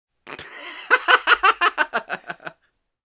Sound effects[edit]
Icon of an audio speaker. Human, Laugh - Comical Laugh: Male - Used for Bowser and Boos in different pitches.
Human,_Laugh_-_Comical_Lauge_-_Male.oga